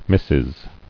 [mis·sis]